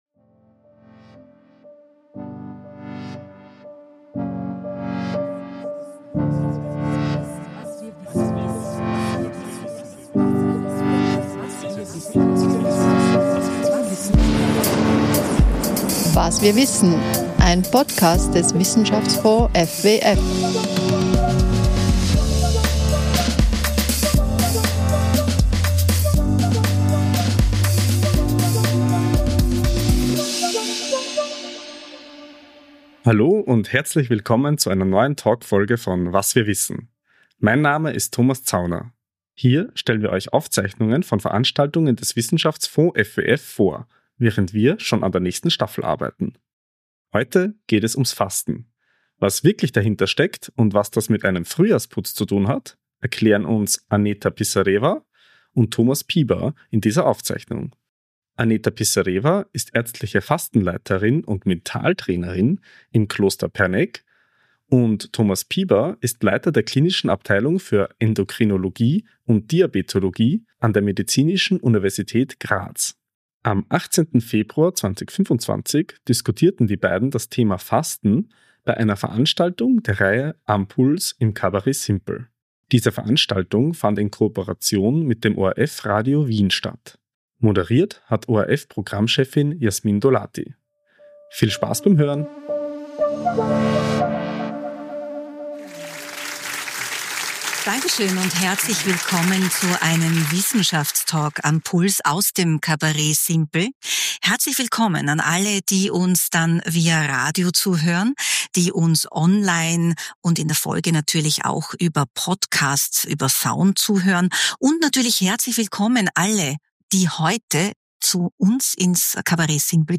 Talk – Jungbrunnen Fasten ~ Was wir wissen Podcast